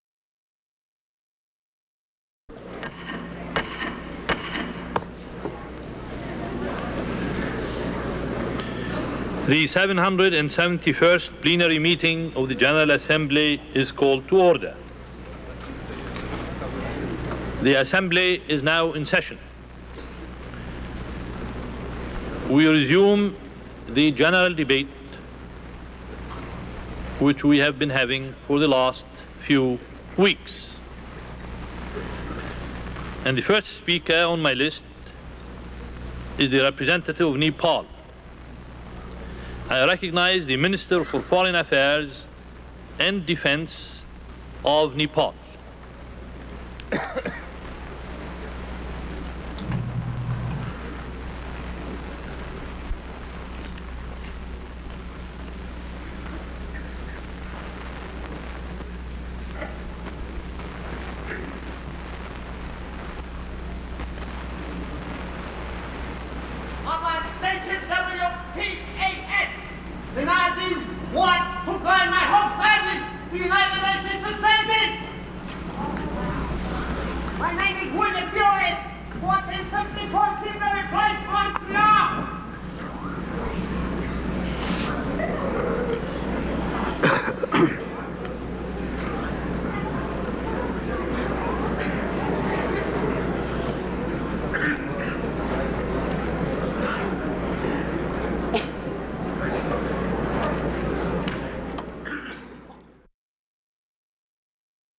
Lebanese representative Charles Malik calls to order the 771st Plenary Session of the U.N. General Assembly and calls on Nepali delegate P. B. Shah to speak. The session is interrupted by a screaming Indian agitator.
Recorded 1958 October 6